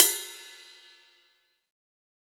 • 2000s Drum Ride F Key 01.wav
Royality free ride tuned to the F note. Loudest frequency: 8057Hz